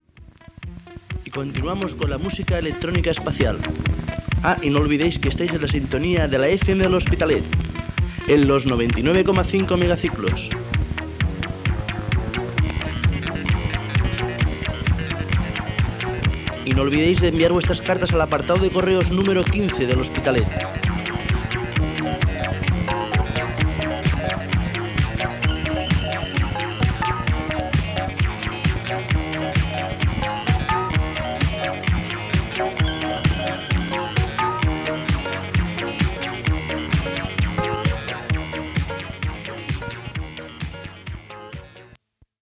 Identificació de l'emissora i apartat de correus.